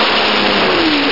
1 channel
BOMB1.mp3